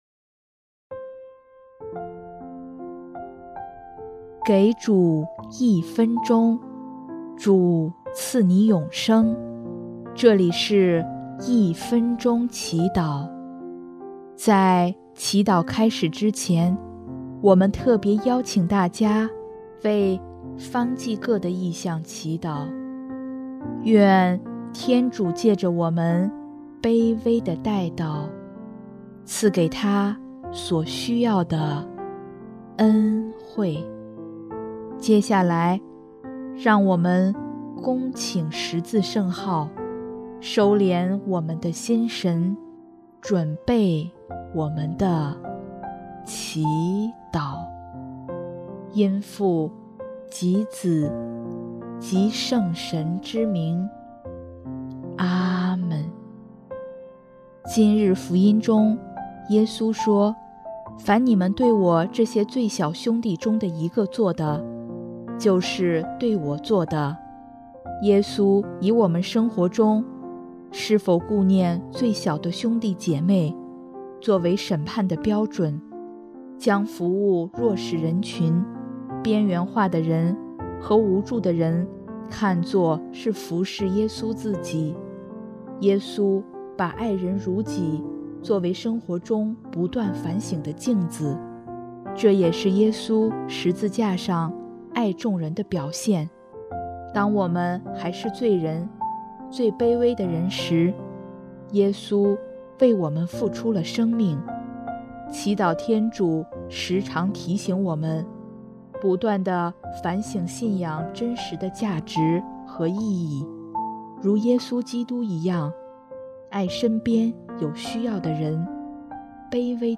【一分钟祈祷】|3月10日 爱人如己